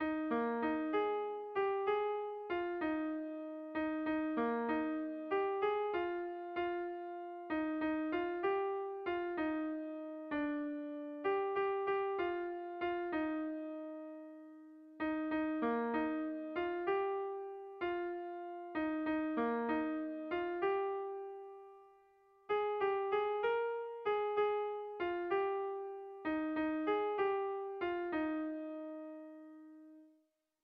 Irrizkoa
Zortziko txikia (hg) / Lau puntuko txikia (ip)
A1BA2D